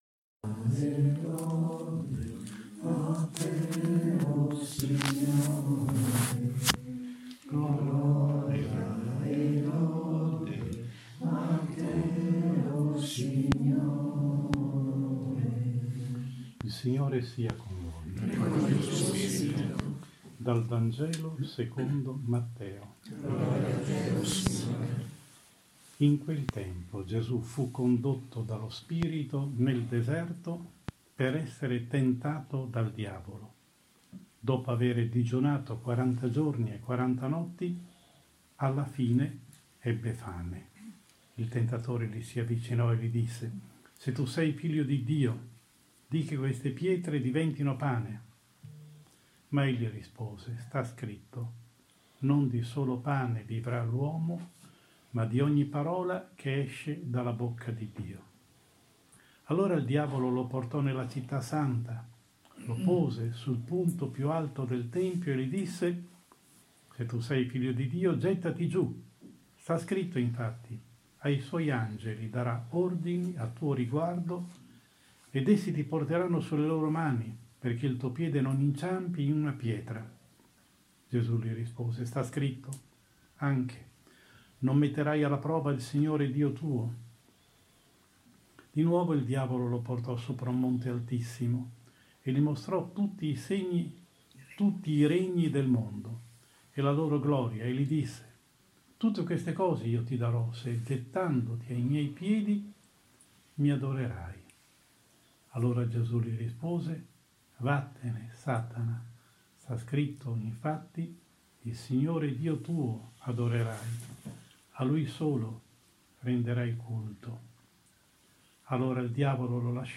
Domenica 1 Marzo 2020 I DOMENICA DI QUARESIMA (ANNO A) – omelia